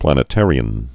(plănĭ-târē-ən)